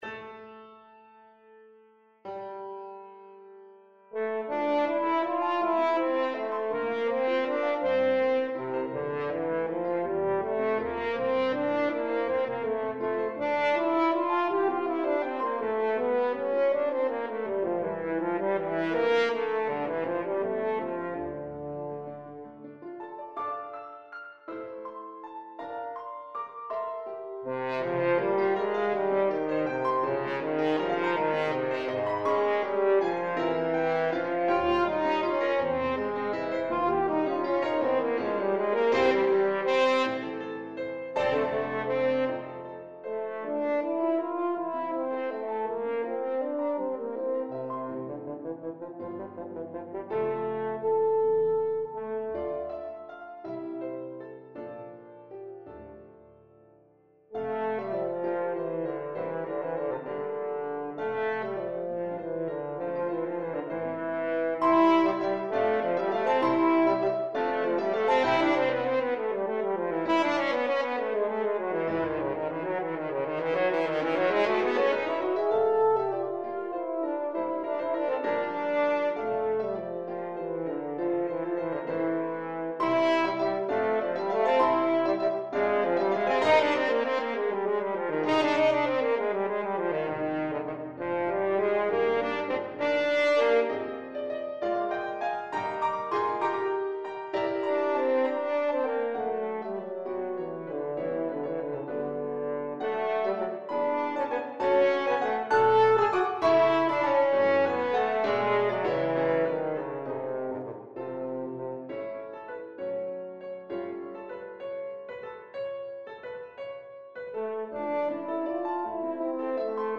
6/8 (View more 6/8 Music)
Classical (View more Classical French Horn Music)